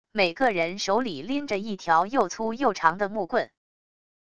每个人手里拎着一条又粗又长的木棍wav音频生成系统WAV Audio Player